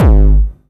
VEC3 Bassdrums Dirty 19.wav